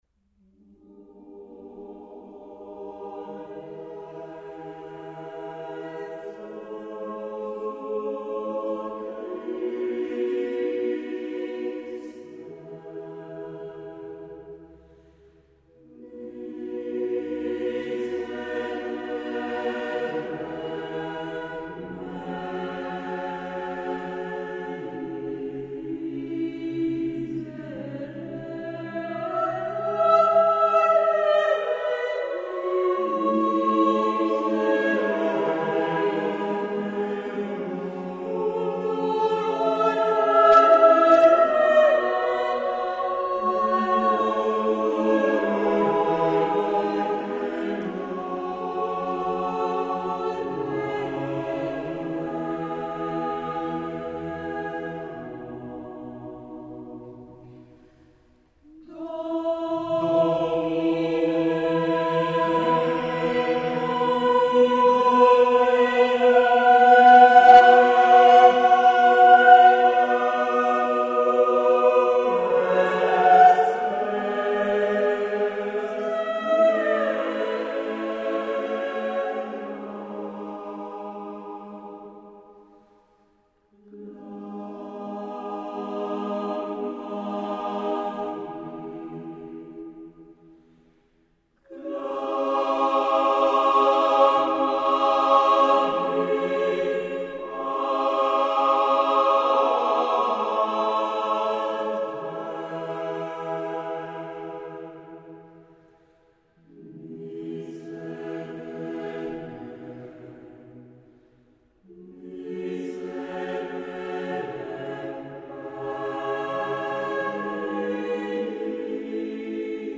CD2 POLIFONIA A CAPPELLA